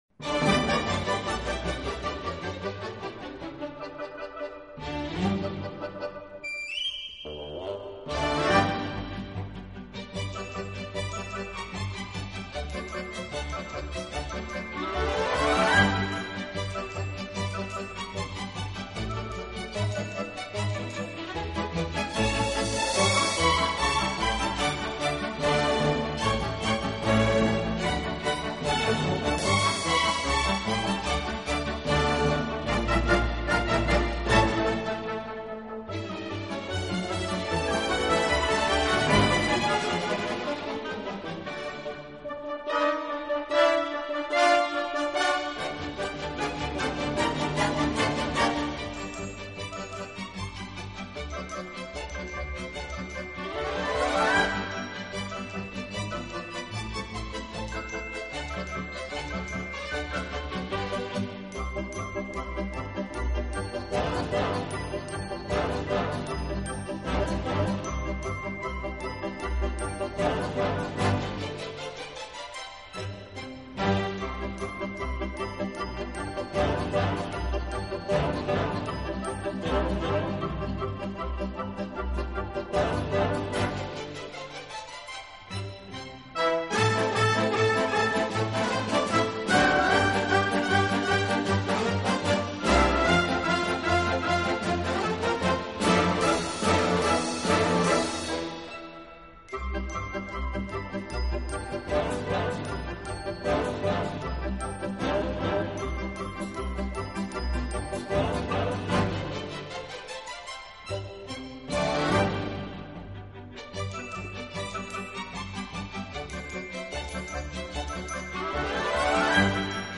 这个乐团的演奏风格流畅舒展，
旋律优美、动听，音响华丽丰满。